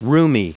Prononciation du mot rheumy en anglais (fichier audio)
Prononciation du mot : rheumy